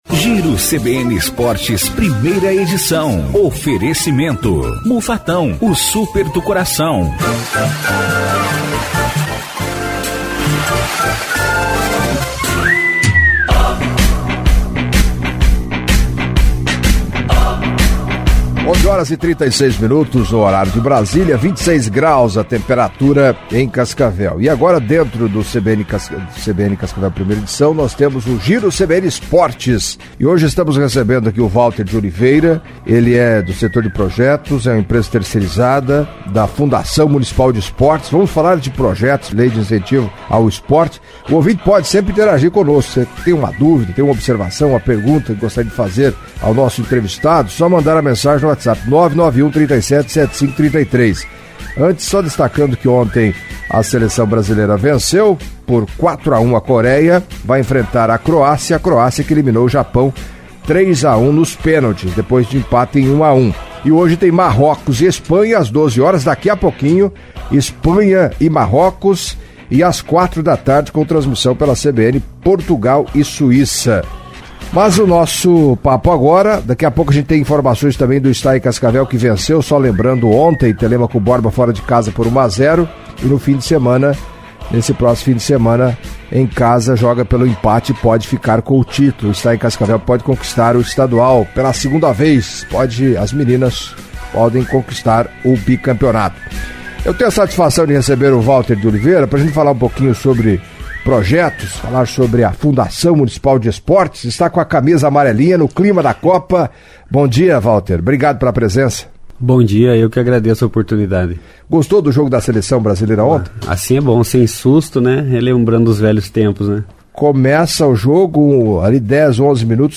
Em entrevista à CBN Cascavel nesta terça-feira